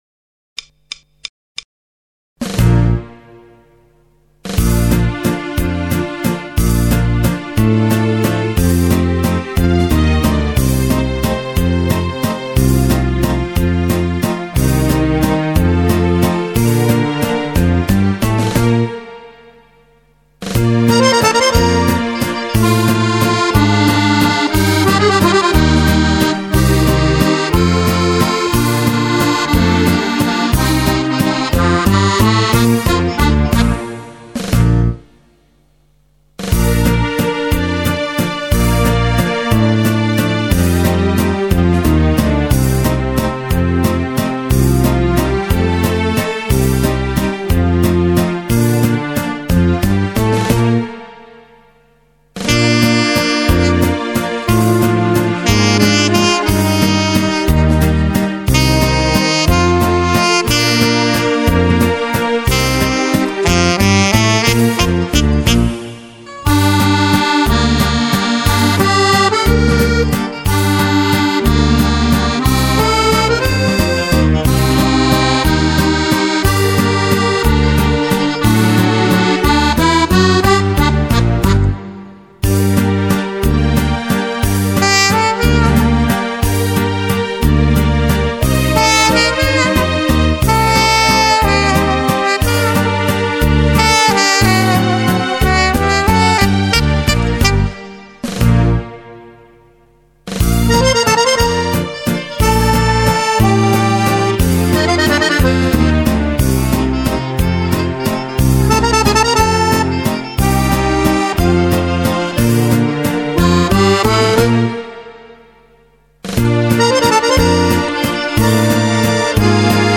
spartito musicale, valzer per fisarmonica e sax
fisarmonica, sax